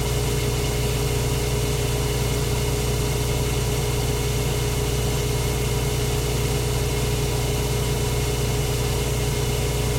centrifugeOperate.ogg